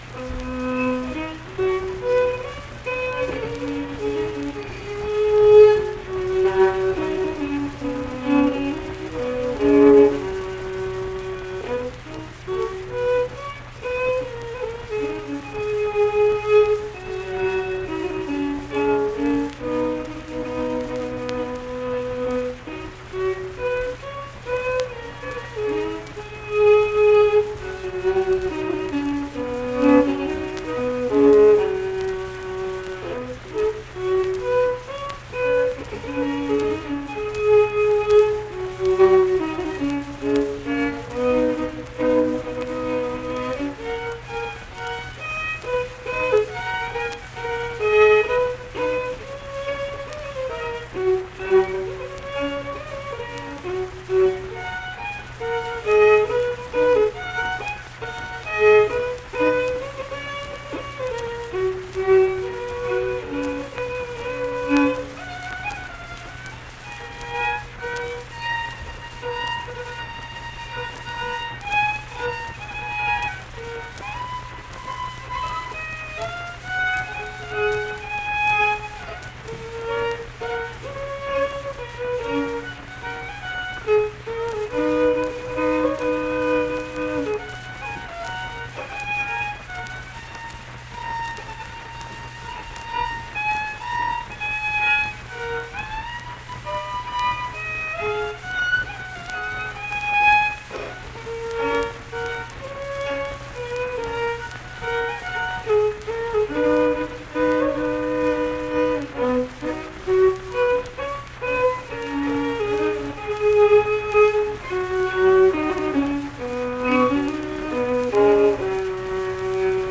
Unaccompanied fiddle music
Performed in Ziesing, Harrison County, WV.
Instrumental Music
Fiddle